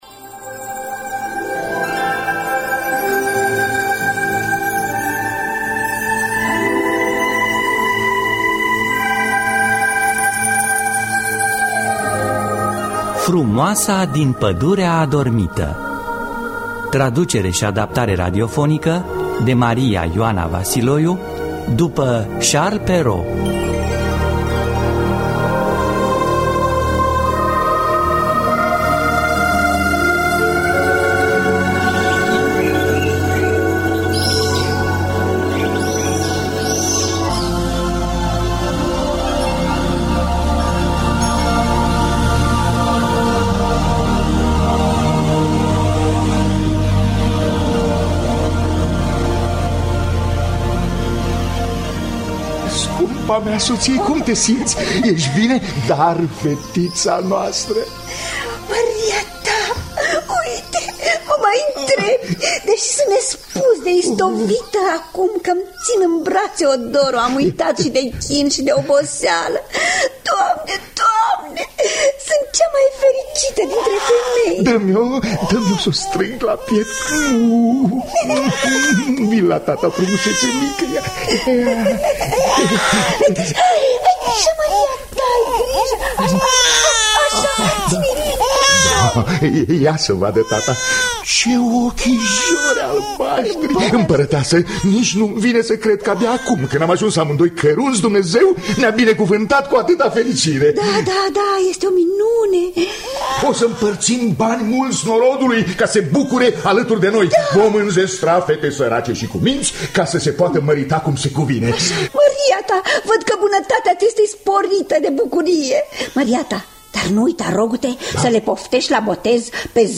Frumoasa din pădurea adormită de Charles Perrault – Teatru Radiofonic Online